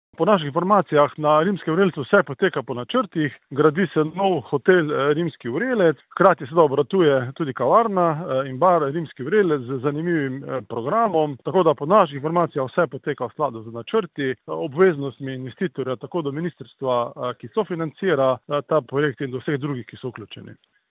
Ali vse poteka po zastavljenih ciljih, smo vprašali župana Občine Ravne na Koroškem Tomaža Rožena: